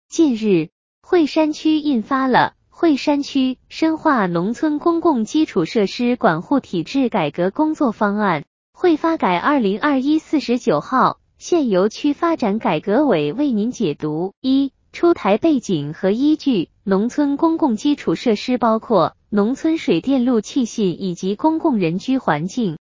政策解读语音版之《深化农村公共基础设施管护体制改革的指导意见》-无锡市惠山区人民政府
政策解读语音版之《无锡市惠山区深化农村公共基础设施 管护体制改革工作方案》.mp3